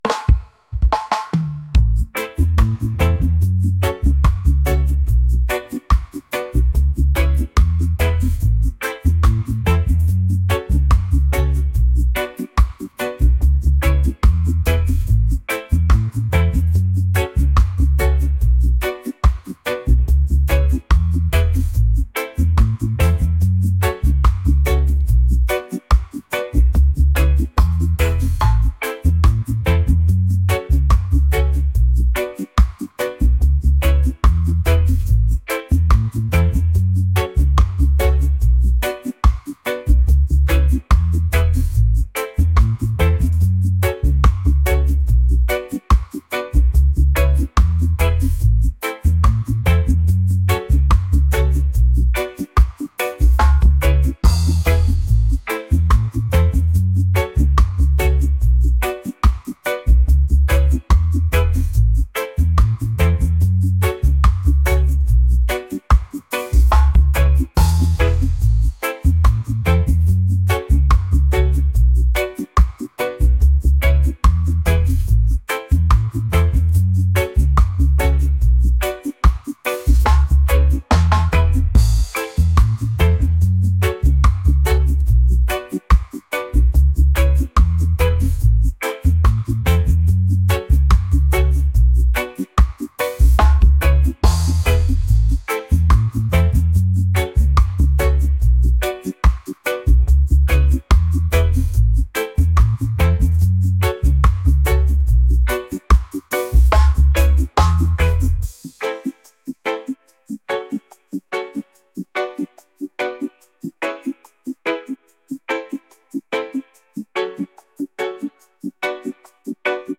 reggae | soul & rnb